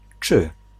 t͡ʂ[3] cz
czy child[4]